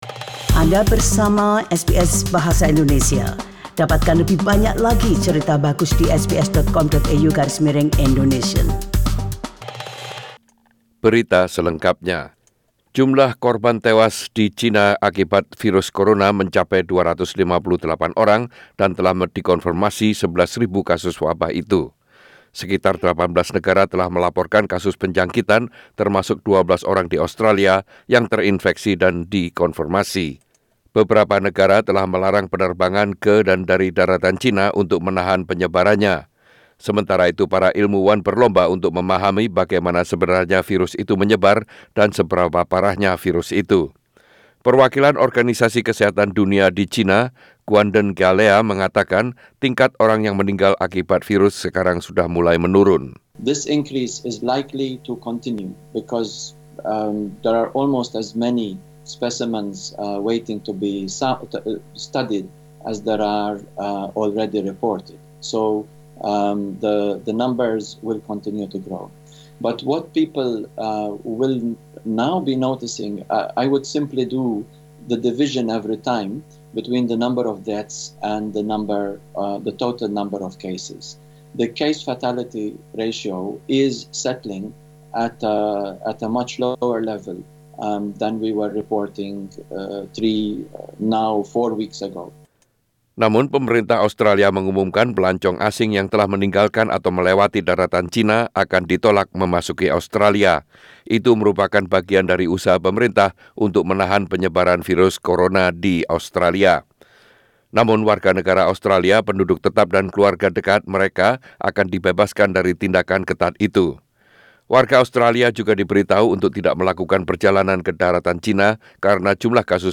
SBS Radio Radio News in Indonesian - 02/02/2020